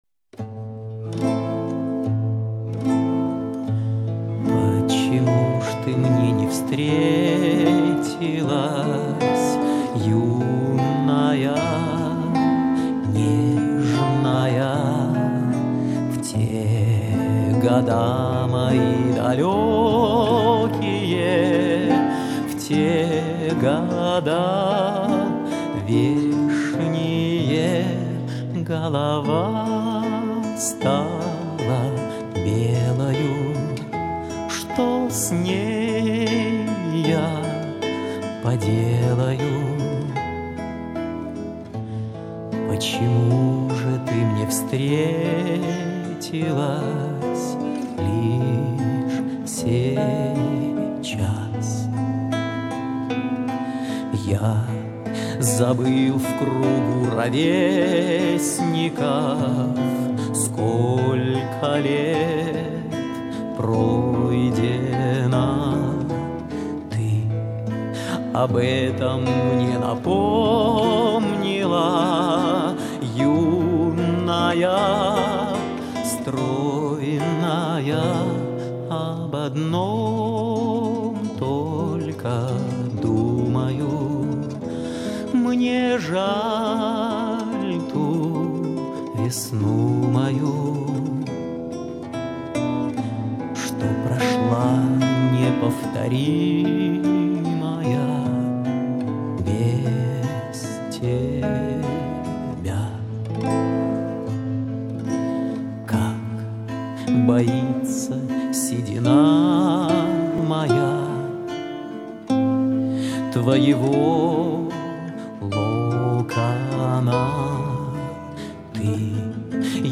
квартет